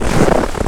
High Quality Footsteps
STEPS Snow, Walk 24-dithered.wav